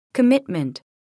미국 [kəmítmənt]